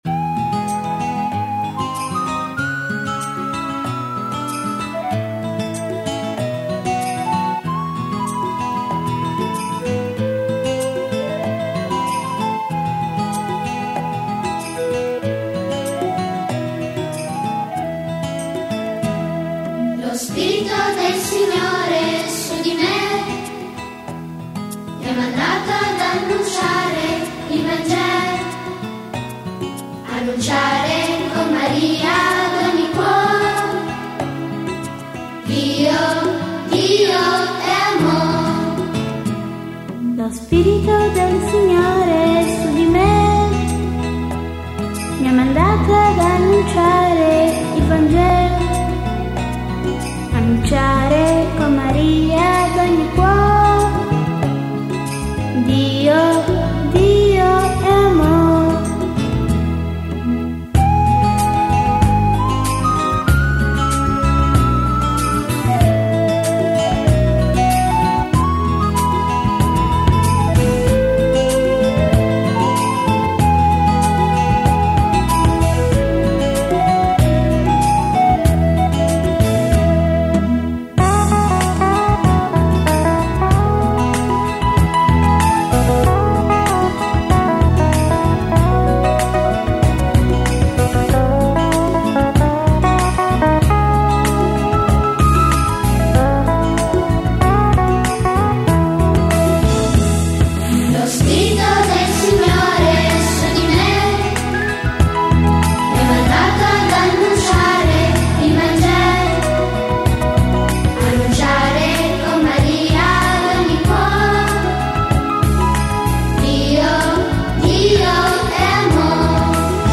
Canto per la Decina di Rosario e Parola di Dio: Lo Spirito del Signore